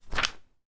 Paper Book Reading » Page Turn 03
Page turns, Book closes, Pagefumbling. Recorded in my home studio using a matched pair of Rode NT5's in the XY configuration. Stereo, Wav, 16bit, 44.1KHz, Unprocessed.
标签： book flick flip magazine newspaper page paper read reading turn